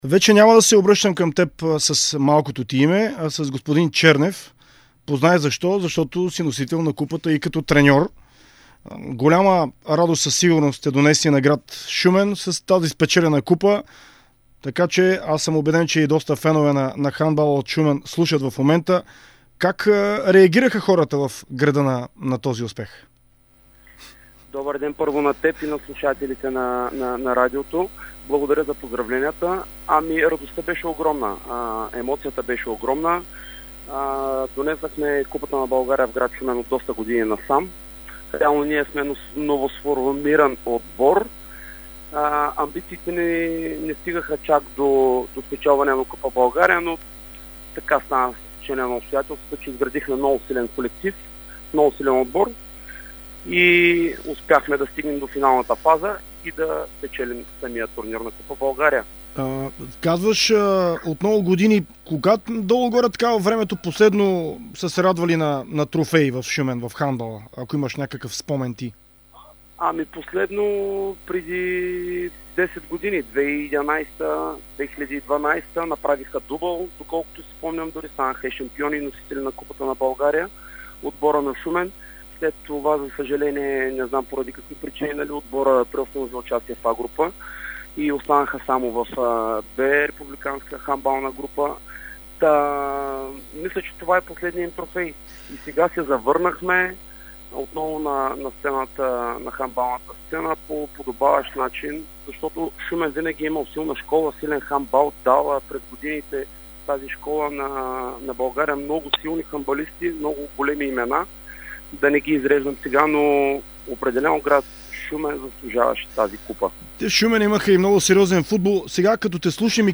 В интервю за Дарик радио и dsport